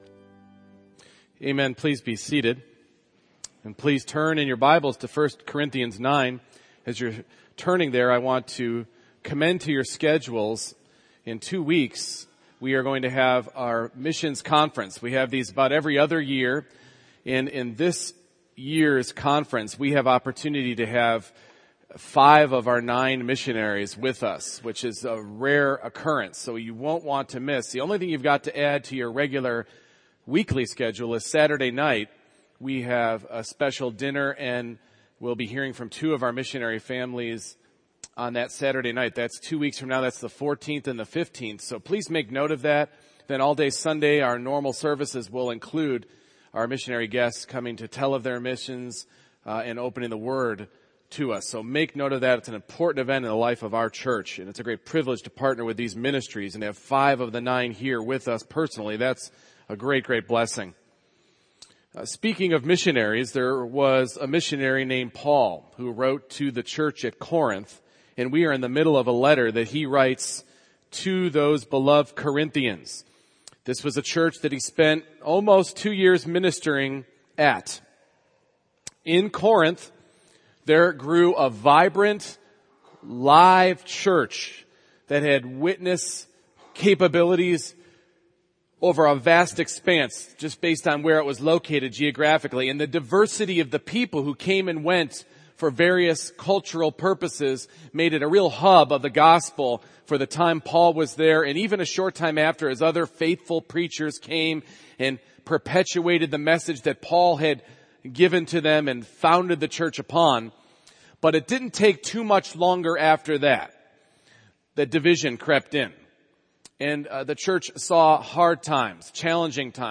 Service Type: Morning Worship Surrendering rights to certain freedoms should be willingly offered for the gospel's sake.